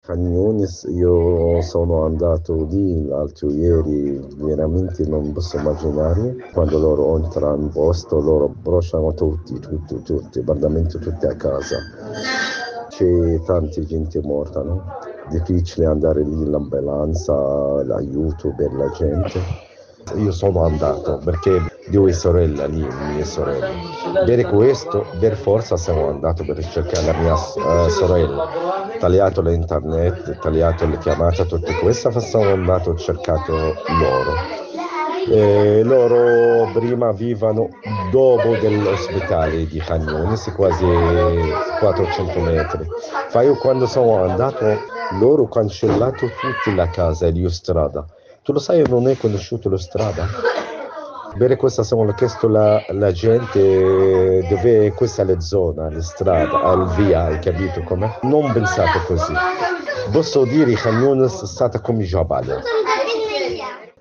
un cittadino palestinese che si trova a Rafah e che nei giorni scorsi è stato proprio a Khan Yunis